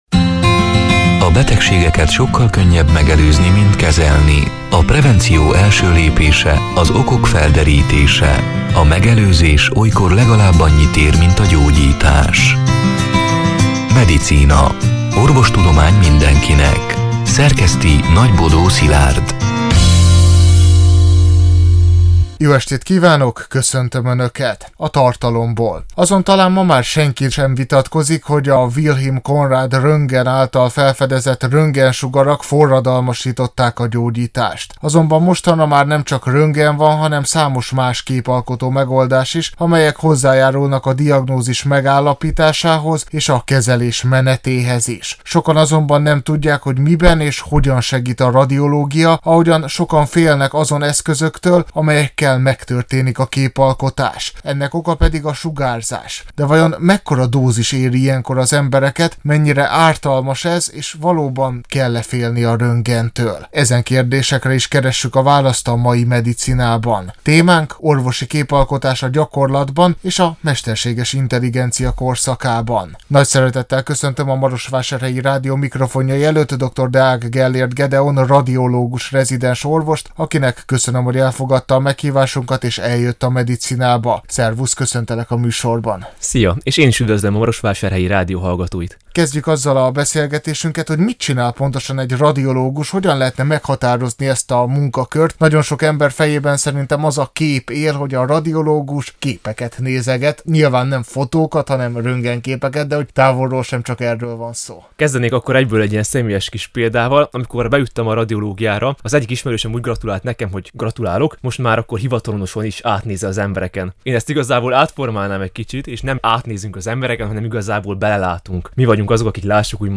A Marosvásárhelyi Rádió Medicina (elhangzott: 2026. március 4-én, szerdán este nyolc órától) c. műsorának hanganyaga: